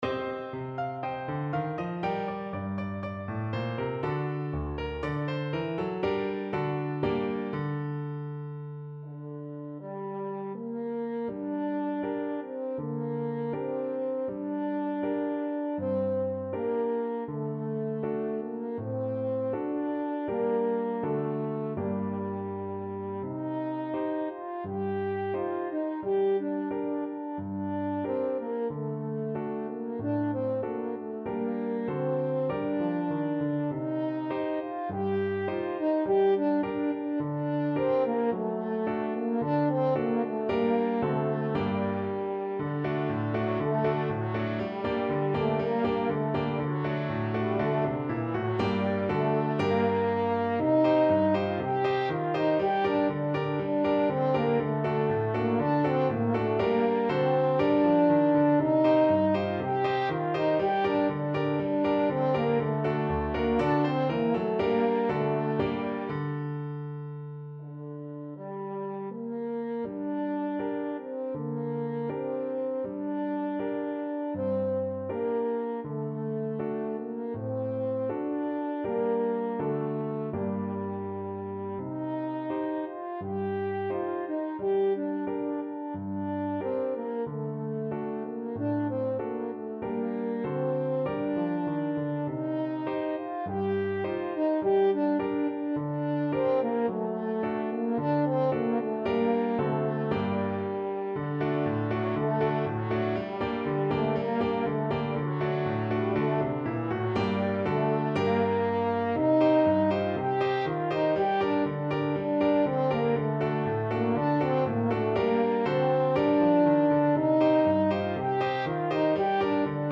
4/4 (View more 4/4 Music)
Allegro =c.120 (View more music marked Allegro)
Traditional (View more Traditional French Horn Music)